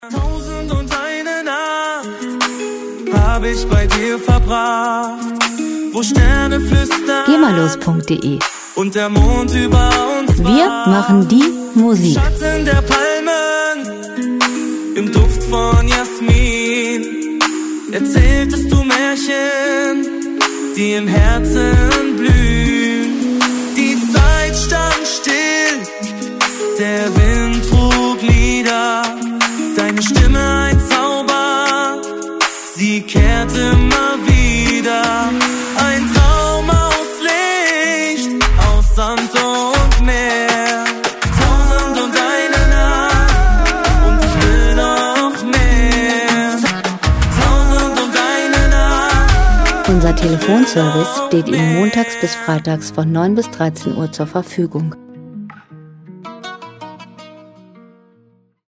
Pop Musik aus der Rubrik: "Popwelt Deutsch"
Musikstil: Oriental Urban Pop
Tempo: 80 bpm
Tonart: As-Moll
Charakter: romantisch, außergewöhnlich